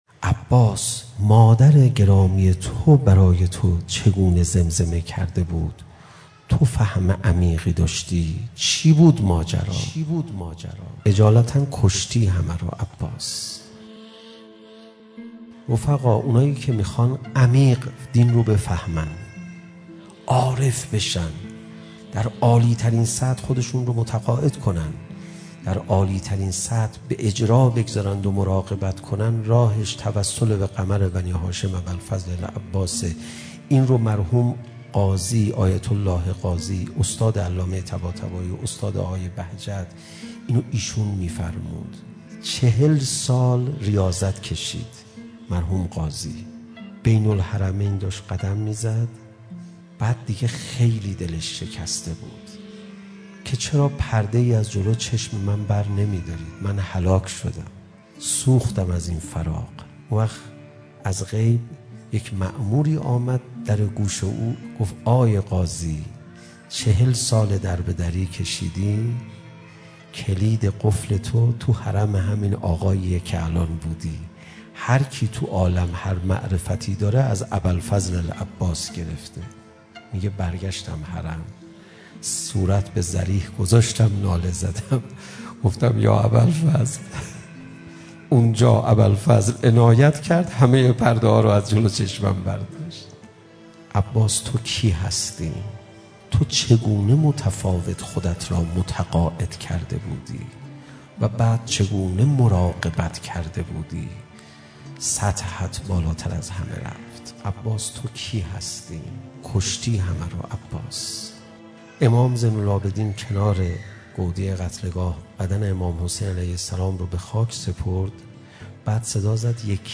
روضه